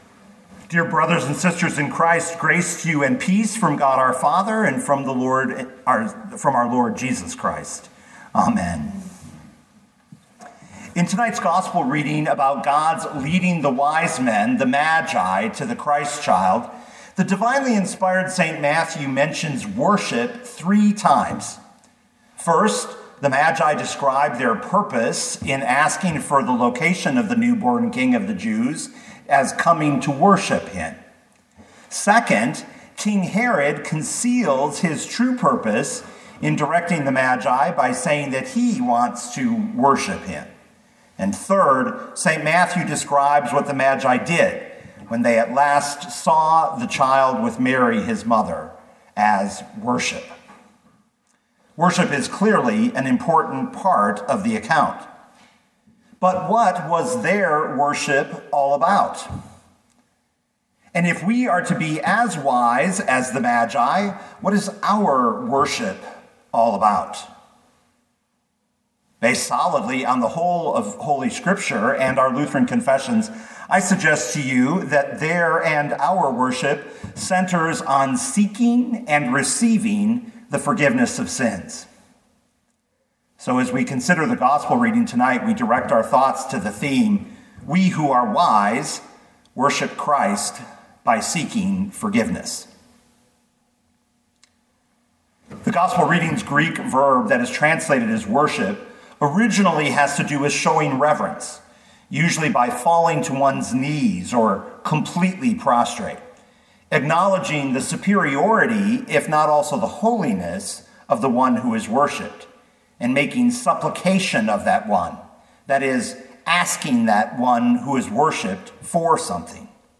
2022 Matthew 2:1-12 Listen to the sermon with the player below, or, download the audio.